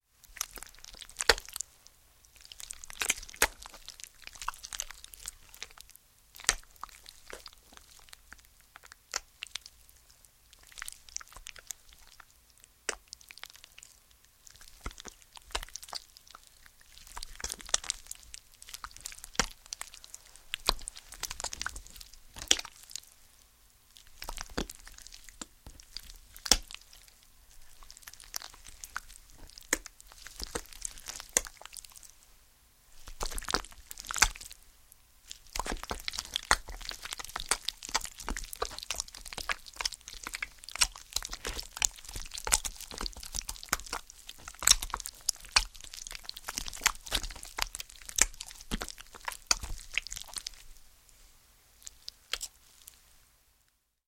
Звуки сосания груди